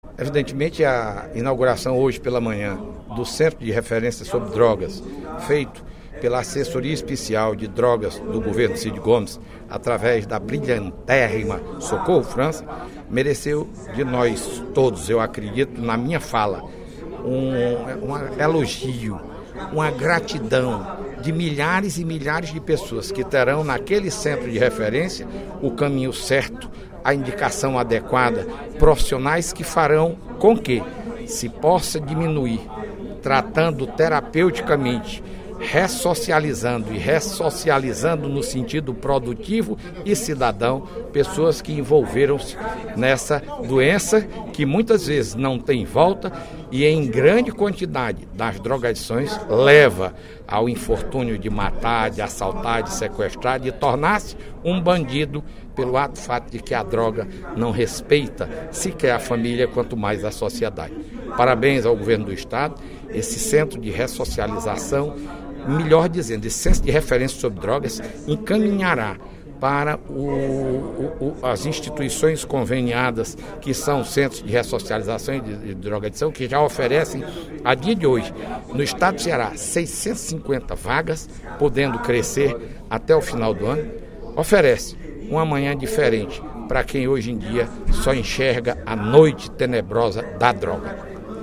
Durante o primeiro expediente da sessão plenária desta quinta-feira (18/12), o deputado Fernando Hugo (SD)  comemorou a inauguração do Centro de Referência sobre Drogas, ocorrida na manhã de hoje.